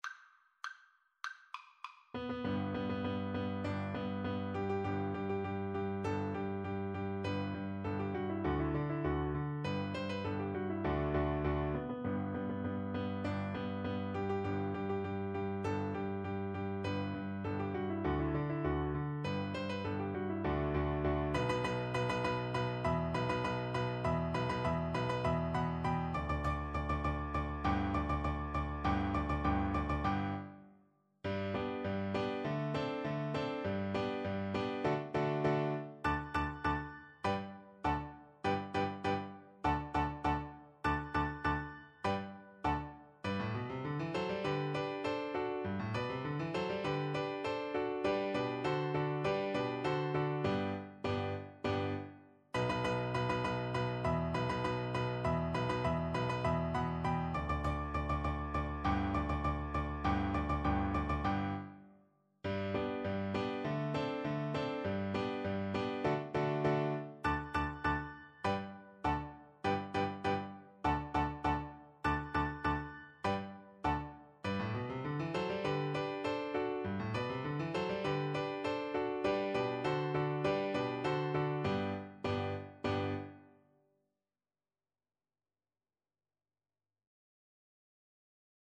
Play (or use space bar on your keyboard) Pause Music Playalong - Piano Accompaniment Playalong Band Accompaniment not yet available reset tempo print settings full screen
G major (Sounding Pitch) (View more G major Music for Viola )
March = c.100
Classical (View more Classical Viola Music)